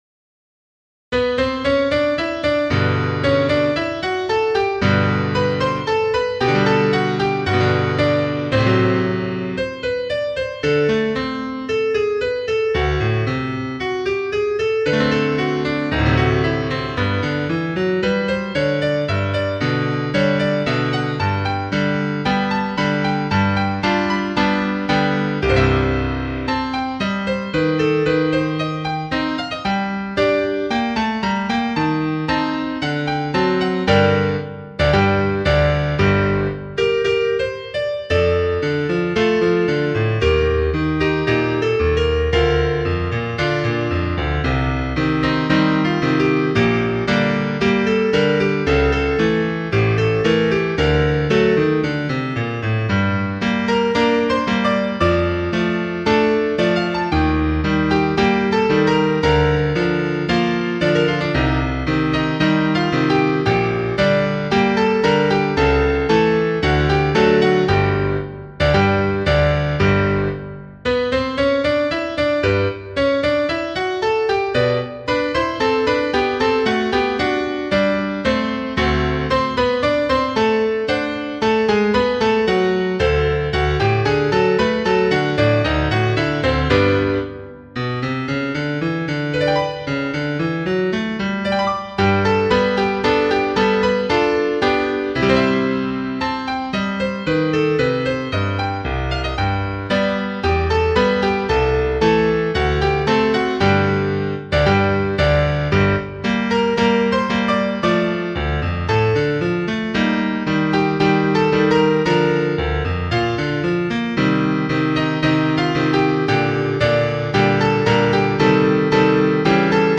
Partitura para piano / Piano score (pdf)
Por una cabeza (tango).mp3